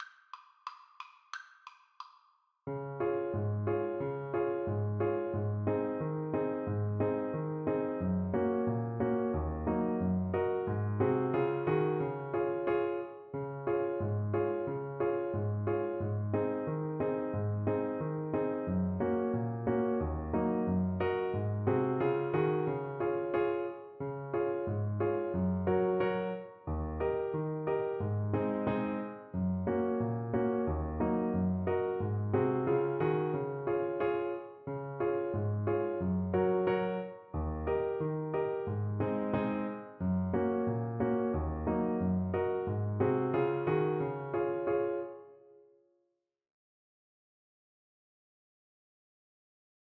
Alto Saxophone
2/2 (View more 2/2 Music)
Hornpipes for Alto Saxophone